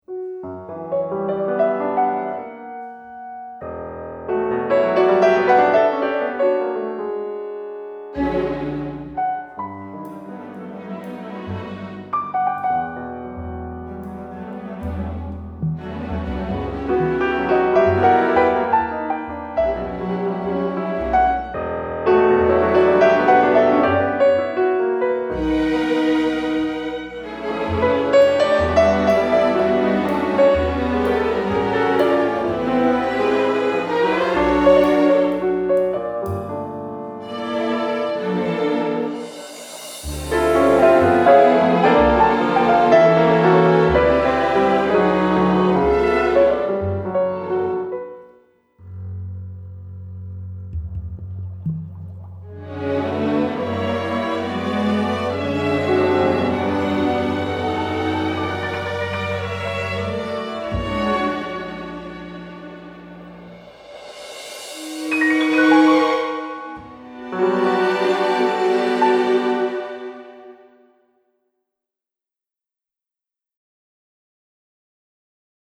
Piano with strings